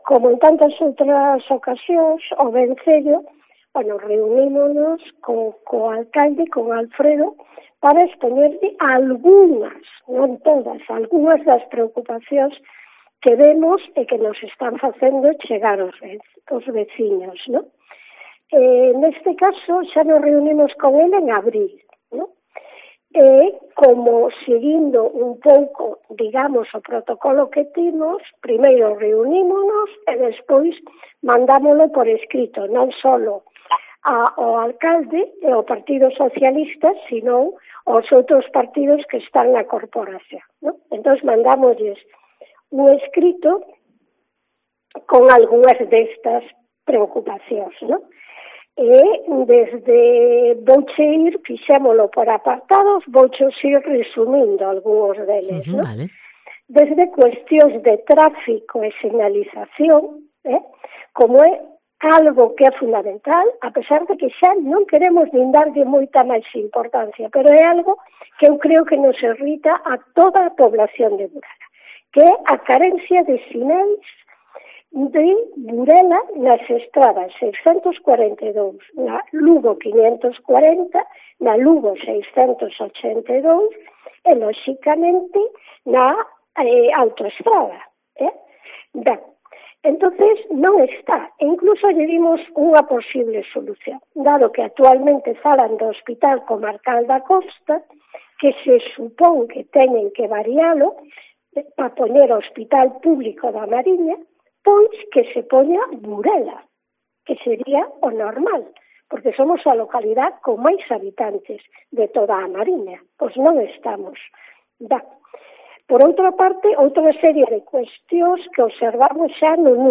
AUDIO: La Asociación de Vecinos O Vencello de Burela ha expuesto hoy, en rueda de prensa, los aspectos que la localidad tiene que mejorar para...